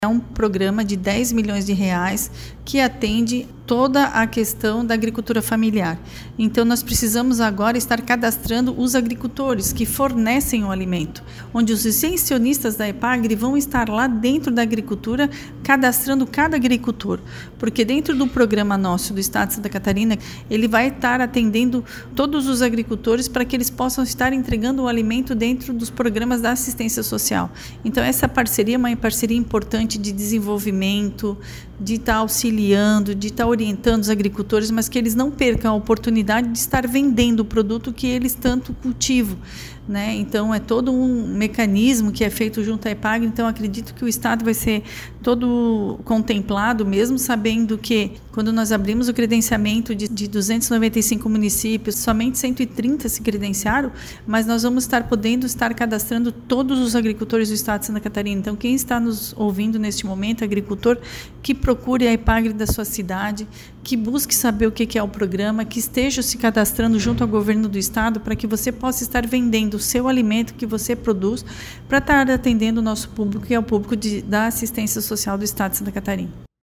A secretária de Assistência Social, Mulher e Família, Maria Helena Zimmermann, explica que para auxiliar os agricultores no cadastramento a SAS fez uma parceria com a Epagri, que por meio dos seus extensionistas em todo o estado está ajudando no processo.